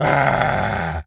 gorilla_angry_04.wav